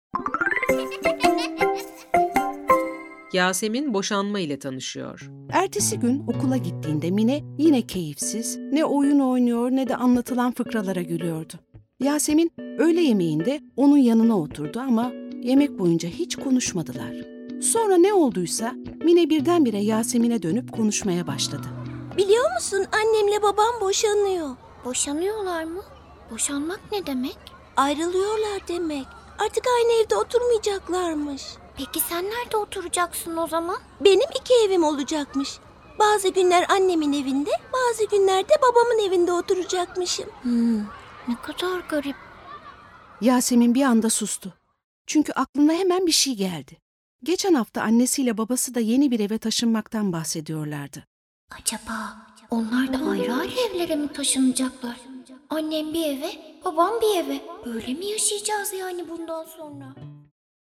Yasemin Boşanma İle Tanışıyor Tiyatrosu